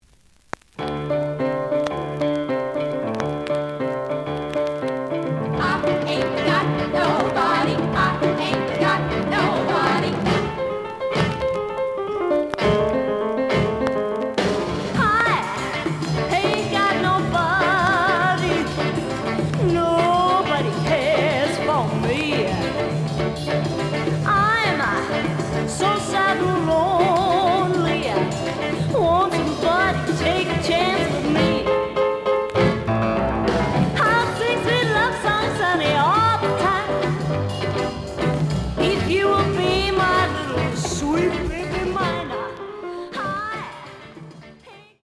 The audio sample is recorded from the actual item.
●Genre: Rhythm And Blues / Rock 'n' Roll
Some click noise on B side due to scratches.)